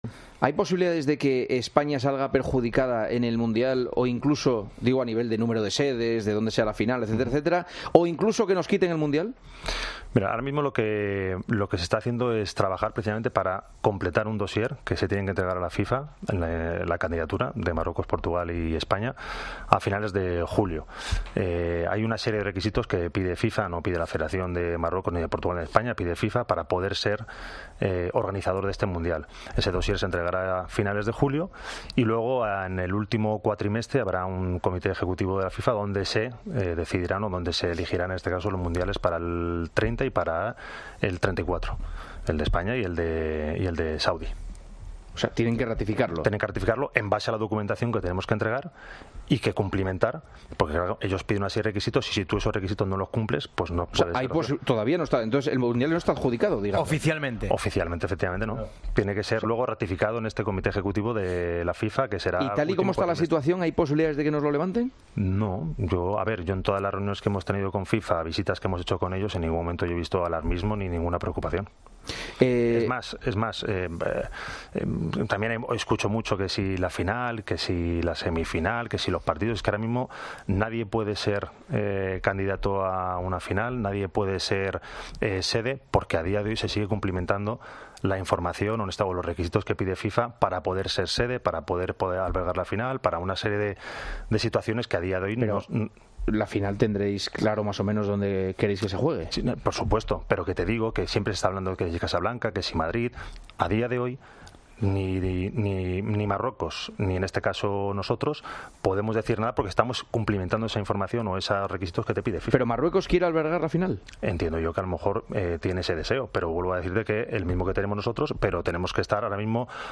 Juanma Castaño charló con Fernando Sanz, que es miembro de la organización, sobre la sede después de que Marruecos esté presionando para llevarse el partido